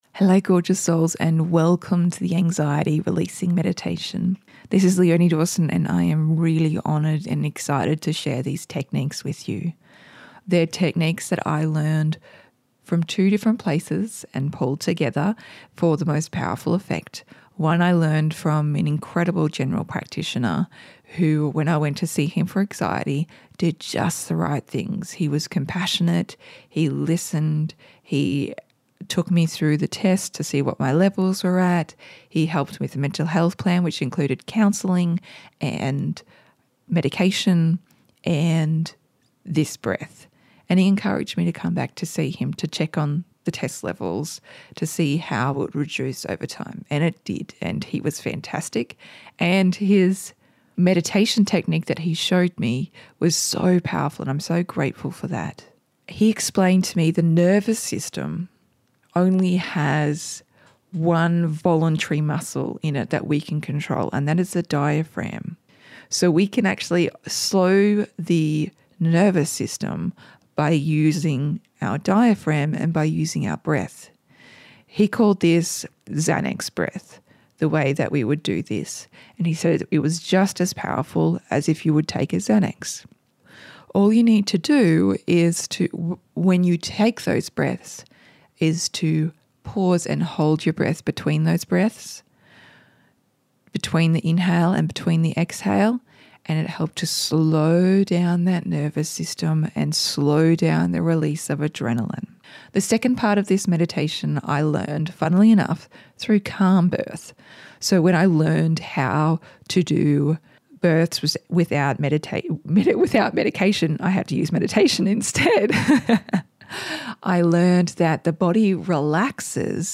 Anxiety_Releasing_Meditation.mp3